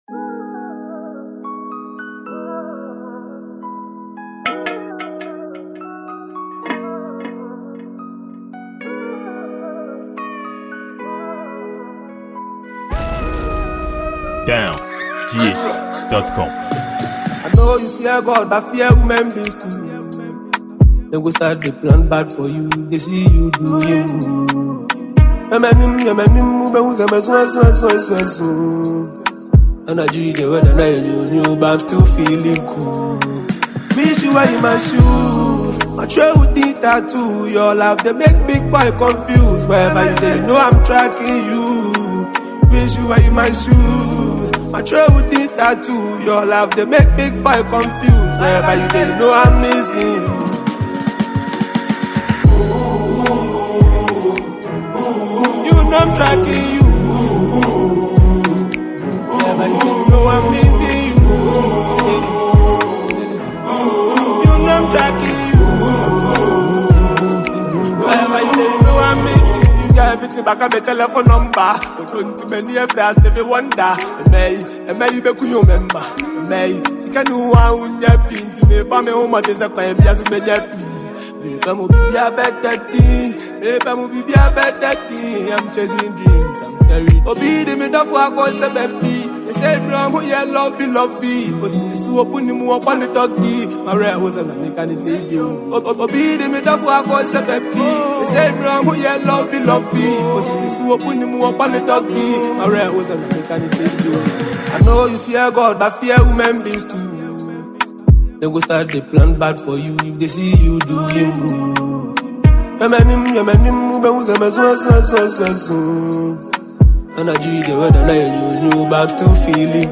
afrobeat song